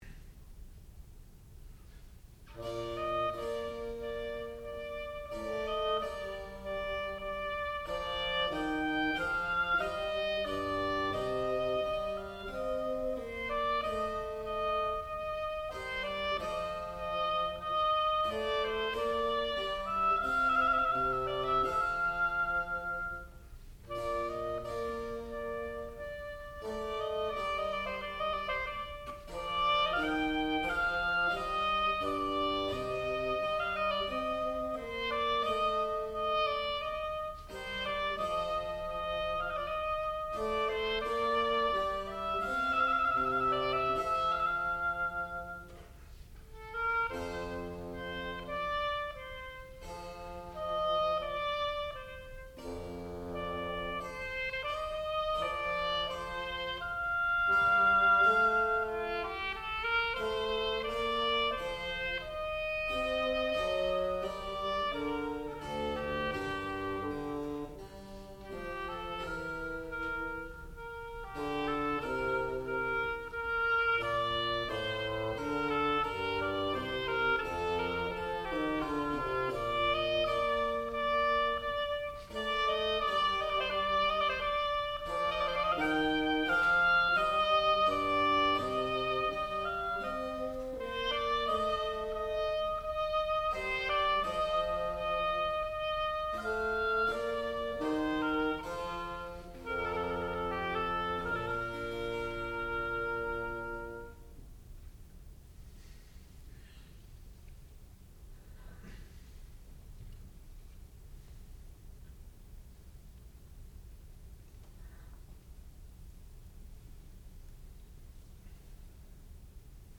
Partita No.1 in B Flat for oboe and continuo
classical music
harpsichord